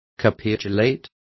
Also find out how capitular is pronounced correctly.